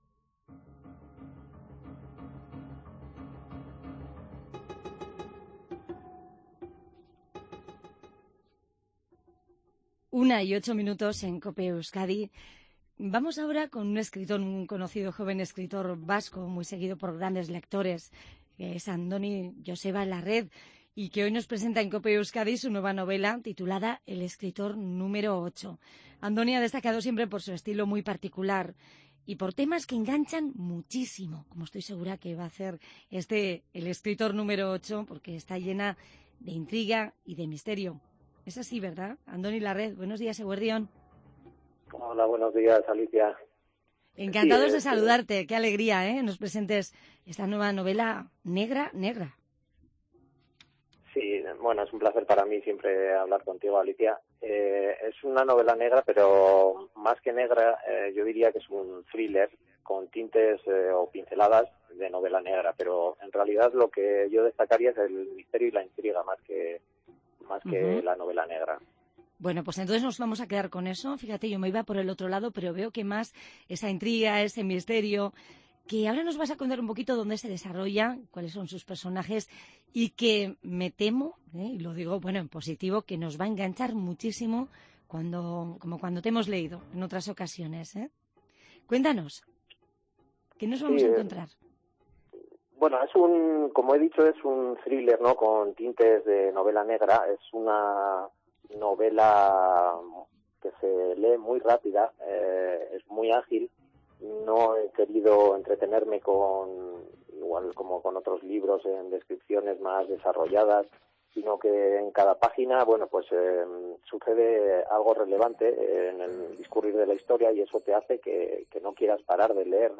ENTREVISTA CULTURA